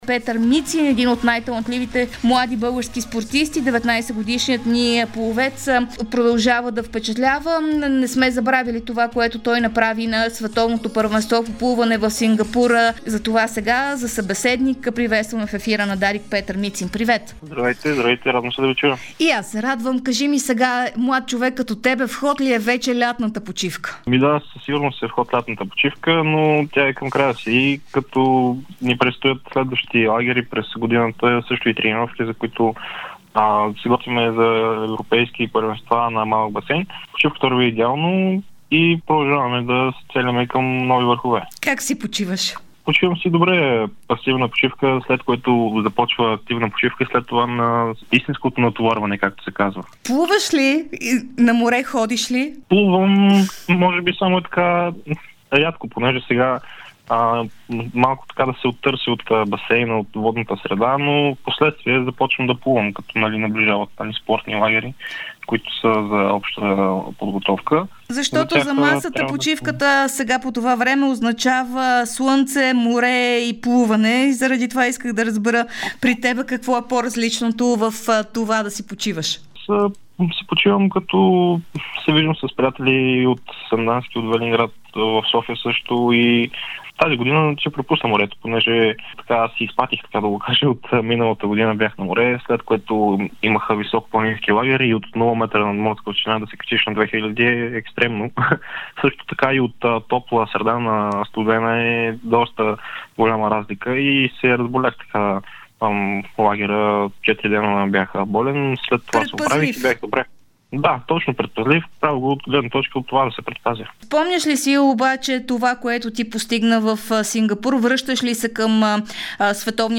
Талантливият български плувец Петър Мицин даде интервю за Дарик радио и Dsport, в което сподели как преминава подготовката му и разкри какви цели си поставя в близко и далечно бъдеще. Той говори и за това дали ще премахне някоя от дисциплините.